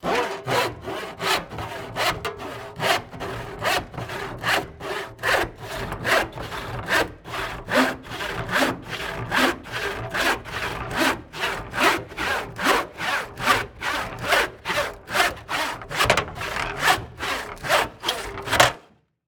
Lyd: Saging håndsag
Saging_håndsag.mp3